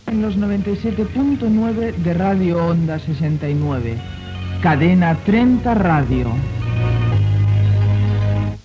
Identificació de l'emissora i de la cadena
FM